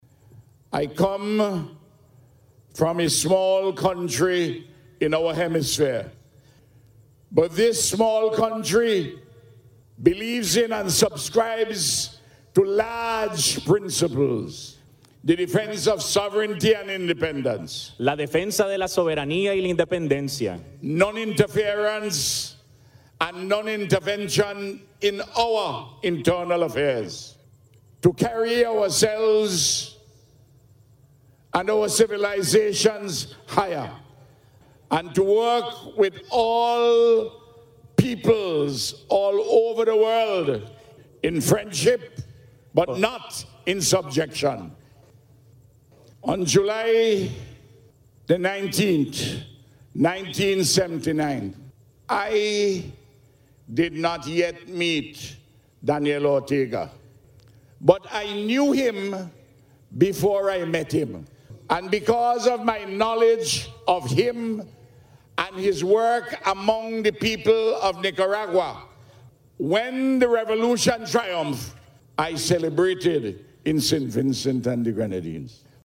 In his address at the Rally, Prime Minister Gonsalves said it is important that people remember the 1979 triumph that restored the rights of Nicaraguan families.